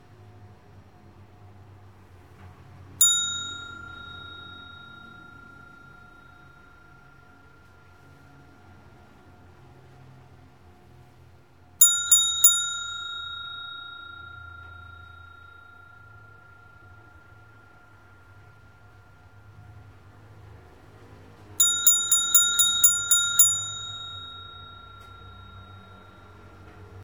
Catégorie l’alarme/reveil